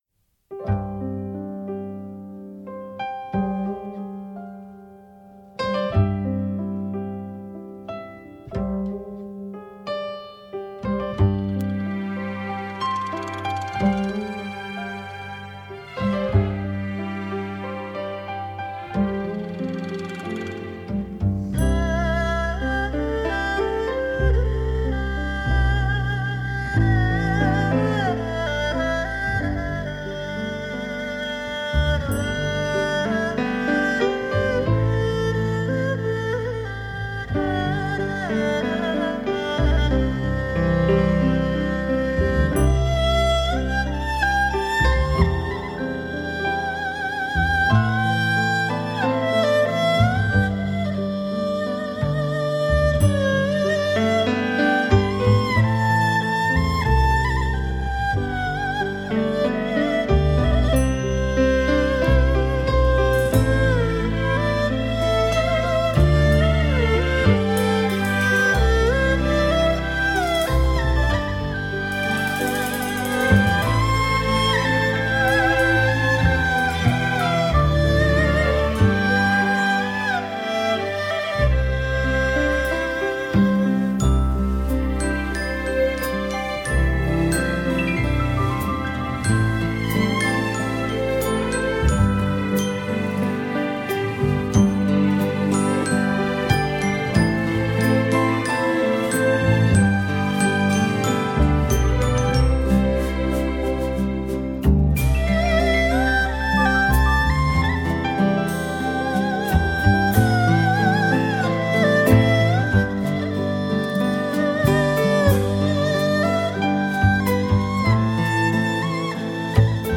今天，FIM使用了最新的DXD技术重新灌录此专辑。
DXD CD采用24比特录制，采样频率高达352.8K赫兹，是超高清晰数字录音技术。